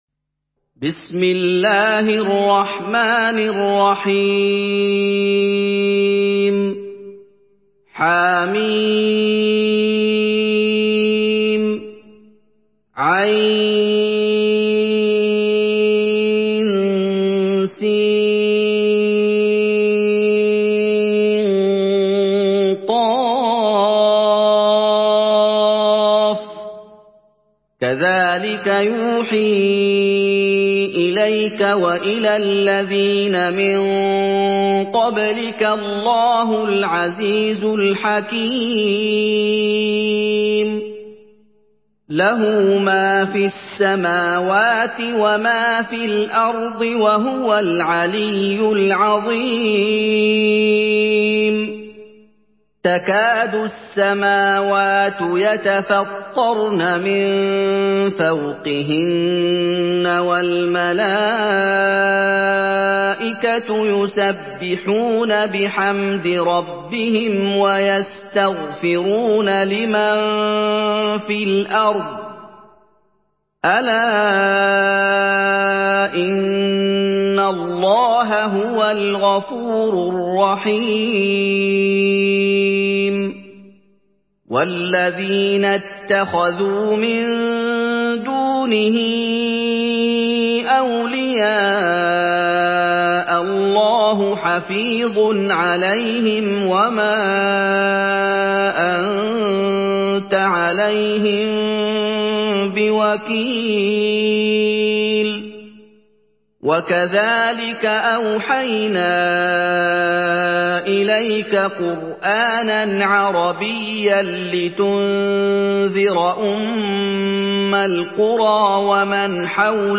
Surah Ash-Shura MP3 in the Voice of Muhammad Ayoub in Hafs Narration
Murattal Hafs An Asim